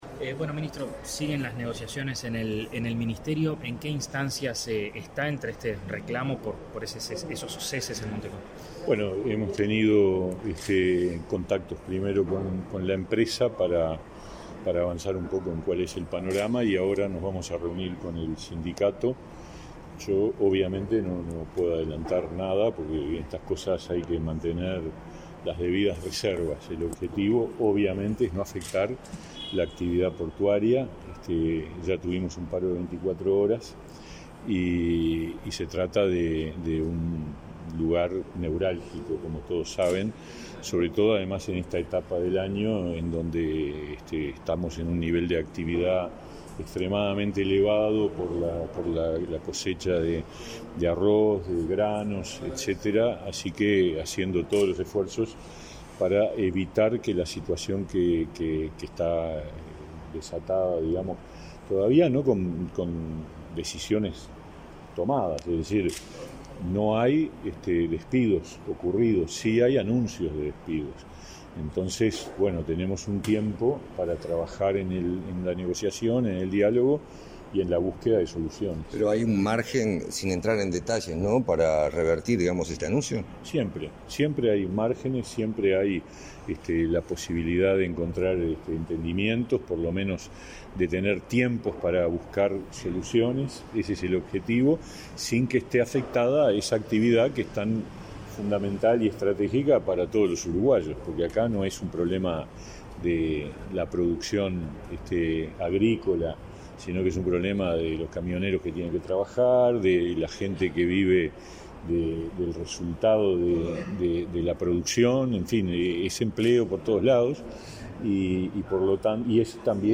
Declaraciones a la prensa del ministro de Trabajo y Seguridad Social, Pablo Mieres
Tras el evento, el ministro efectuó declaraciones a la prensa.